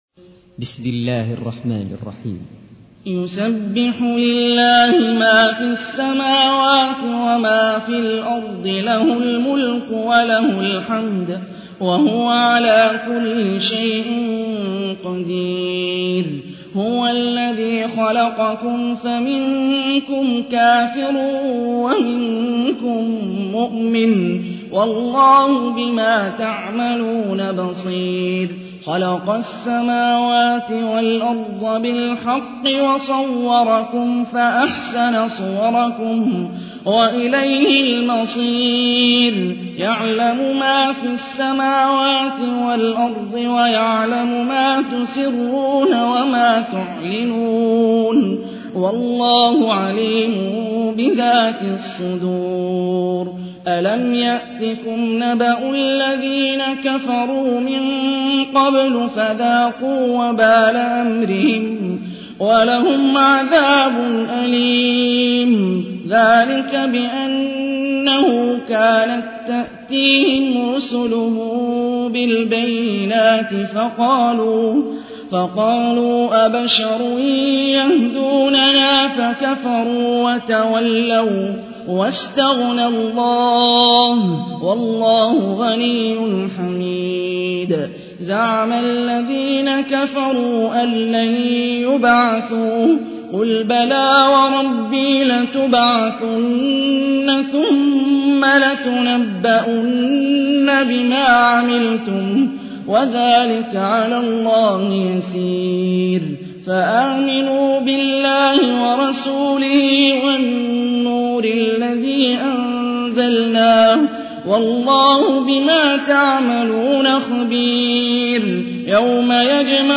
تحميل : 64. سورة التغابن / القارئ هاني الرفاعي / القرآن الكريم / موقع يا حسين